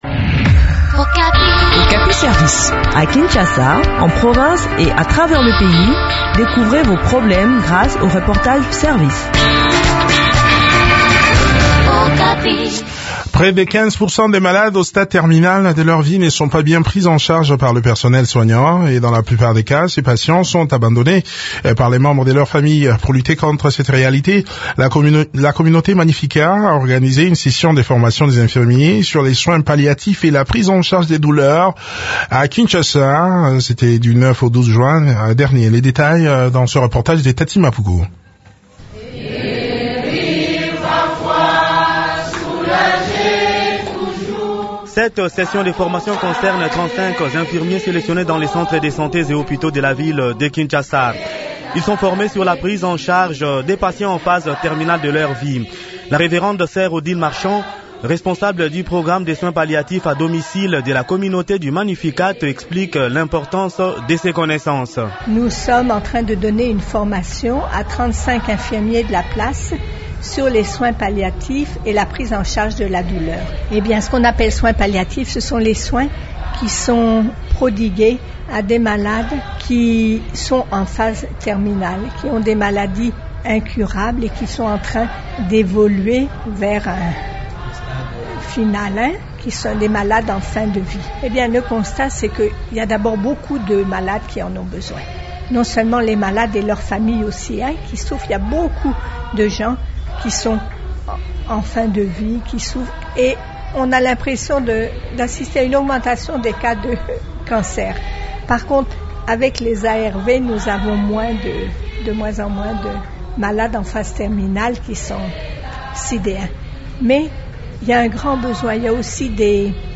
Le point sur le déroulement de cette session de formation dans cet entretien